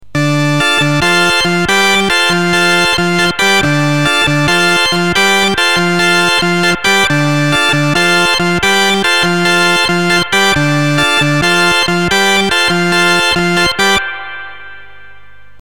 Demo3: Same riff, but 16', 8' and 4'